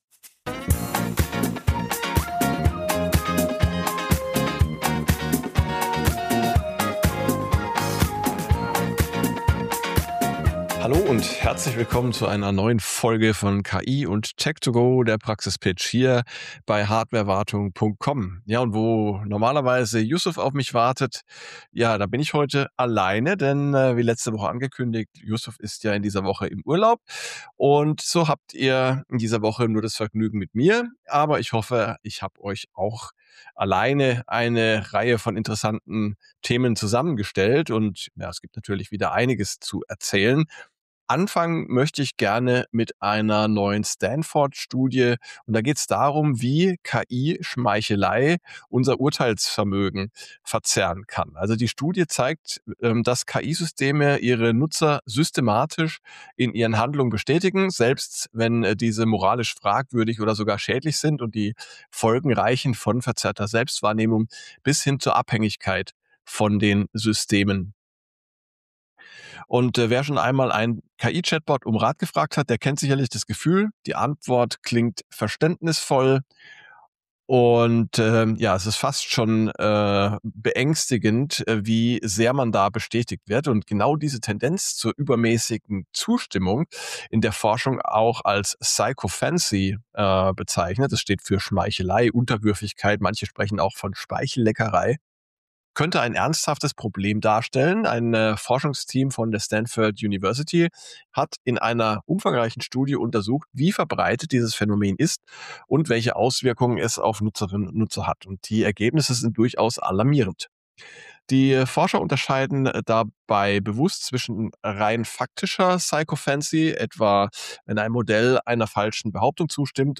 In dieser Solo-Folge